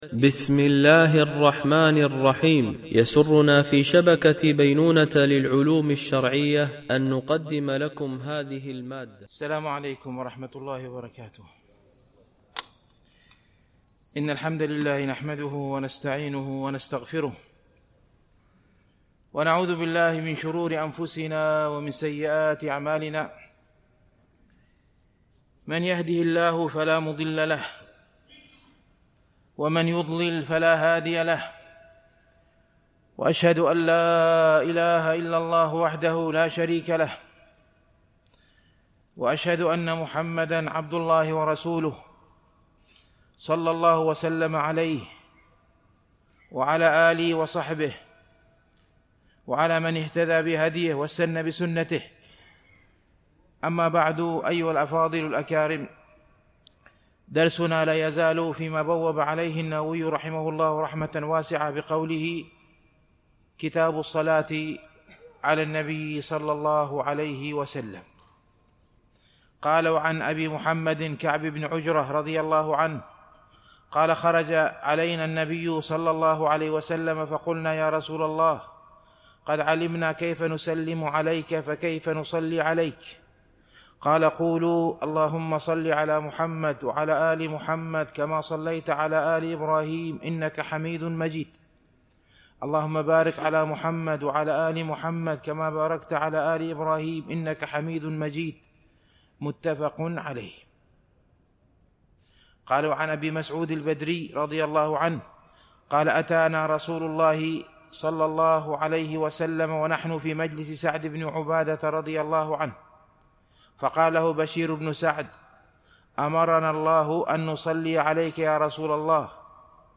MP3 Mono 44kHz 64Kbps (CBR)